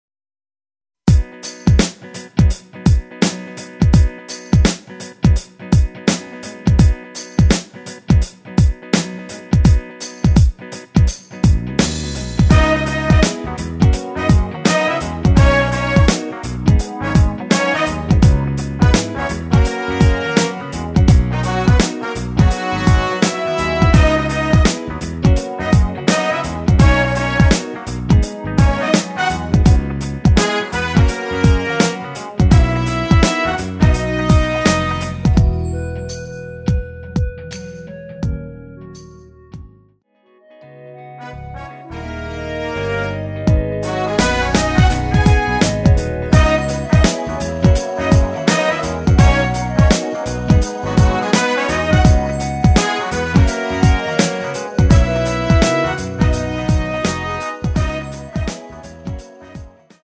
엔딩이 페이드 아웃 이라 엔딩 부분 만들어 놓았습니다.
원키에서(-2)내린 멜로디 포함된 MR입니다.
앞부분30초, 뒷부분30초씩 편집해서 올려 드리고 있습니다.
중간에 음이 끈어지고 다시 나오는 이유는